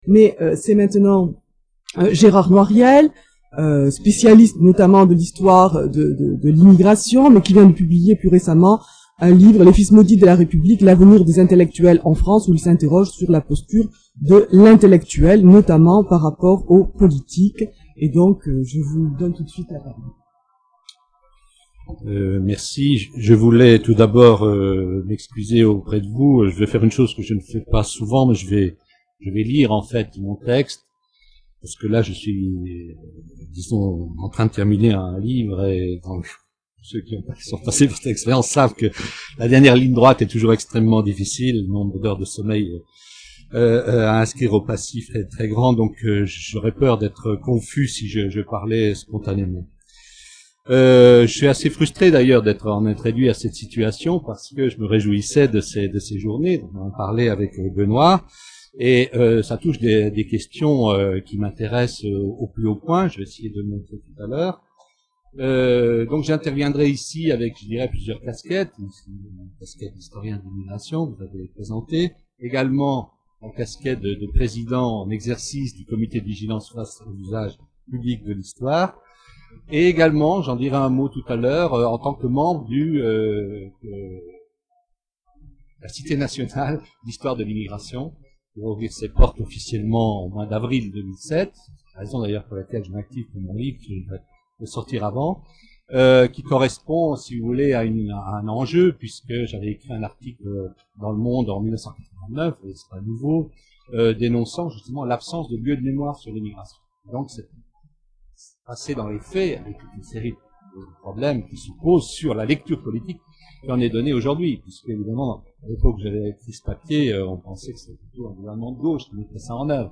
Conférence de Gérard Noiriel, ENS, EHESS ( Ecole des Hautes Etudes en Sciences Sociales)